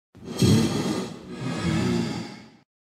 Звуки ифрита
На этой странице собраны звуки Ифрита — одного из самых опасных существ Нижнего мира в Minecraft. Здесь вы можете скачать или слушать онлайн его угрожающее рычание, звуки атак, возгорания и другие эффекты.